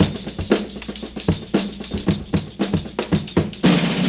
• 117 Bpm HQ Breakbeat Sample G Key.wav
Free drum loop - kick tuned to the G note. Loudest frequency: 3353Hz
117-bpm-hq-breakbeat-sample-g-key-pRV.wav